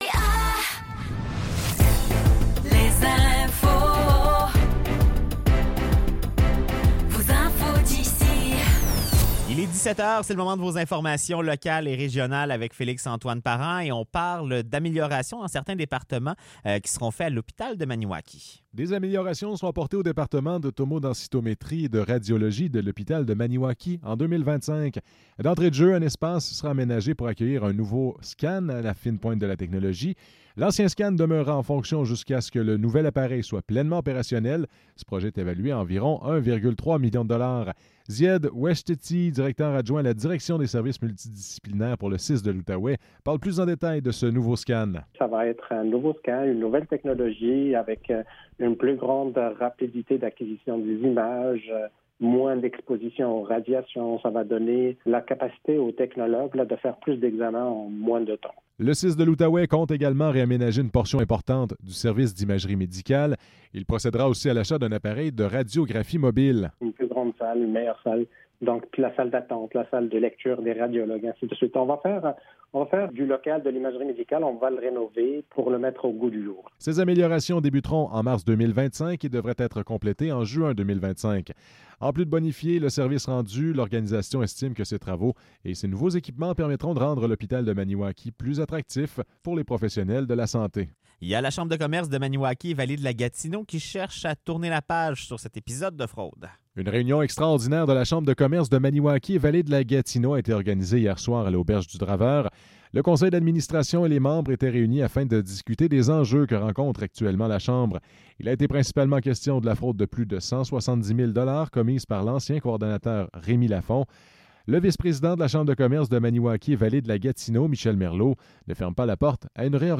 Nouvelles locales - 30 octobre 2024 - 17 h